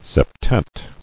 [sep·tet]